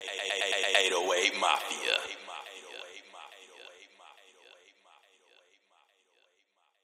[ACD] - 808MafiaMaleVoice.wav